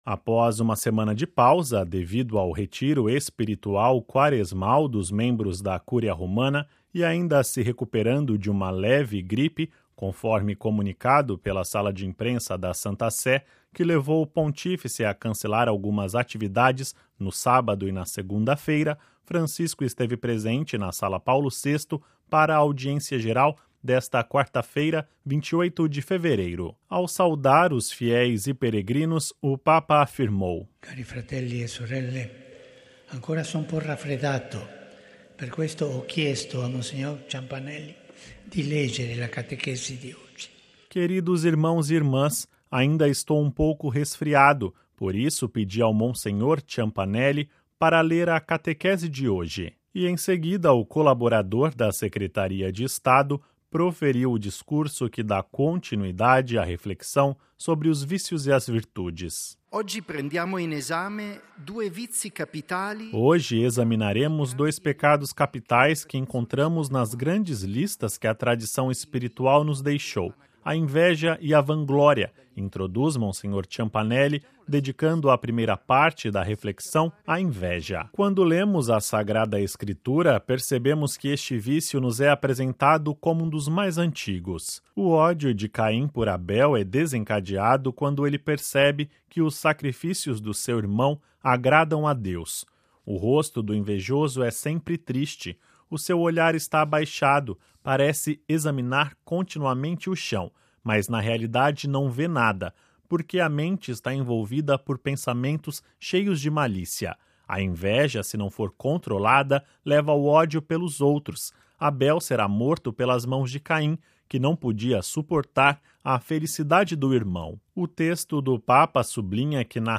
Ouça com a voz do Papa e compartilhe
Audiência Geral com o Papa Francisco